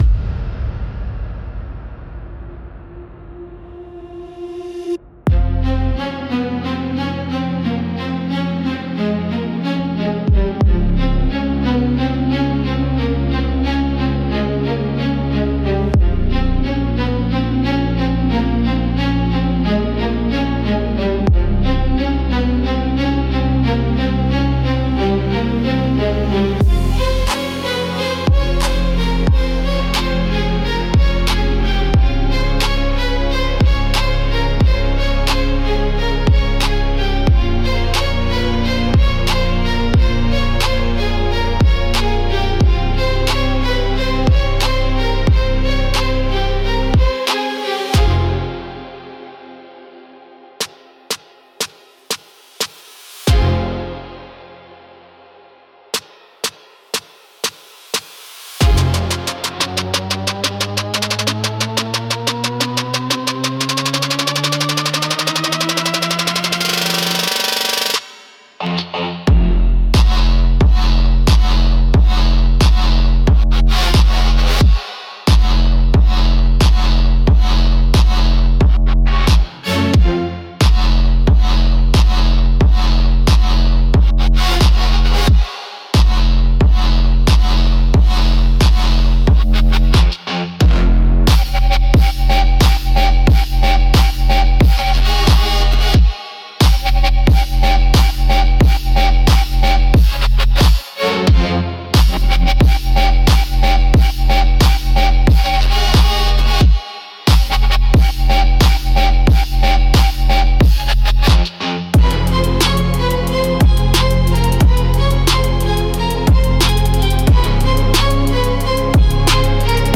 Instrumental - Battleflag Broadcast - Real Liberty Media - 3.22